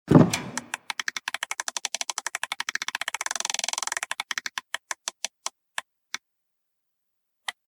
wheel_spin_01.mp3